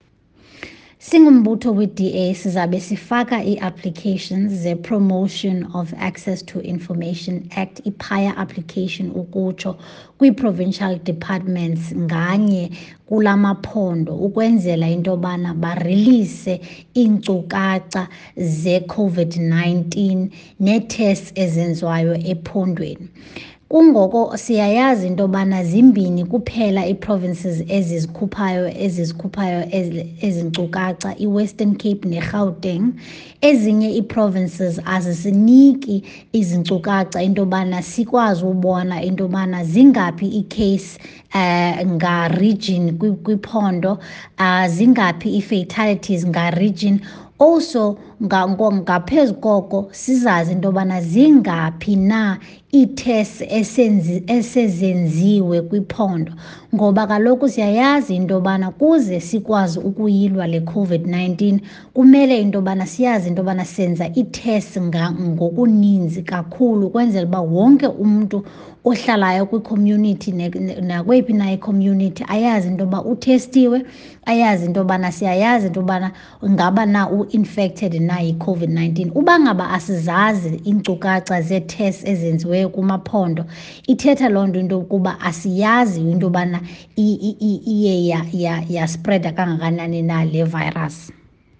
isiXhosa soundbites by Siviwe Gwarube MP, DA Shadow Minister of Health.